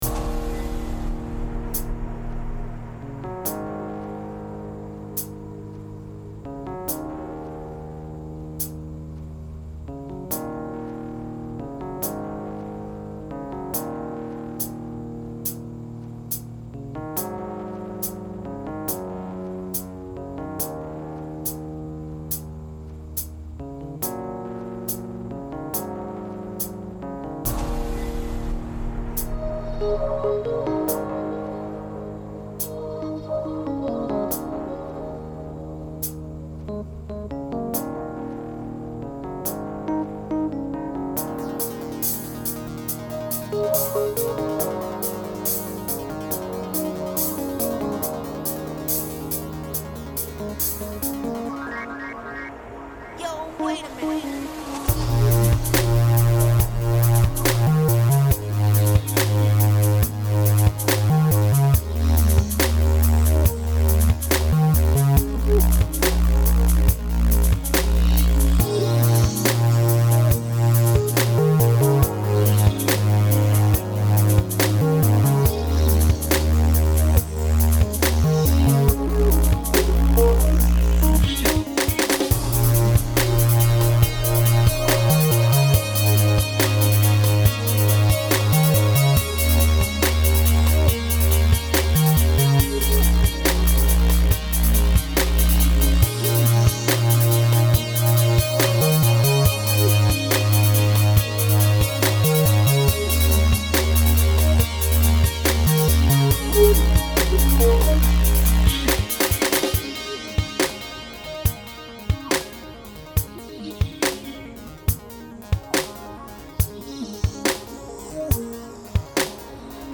has been hard at work in the studio again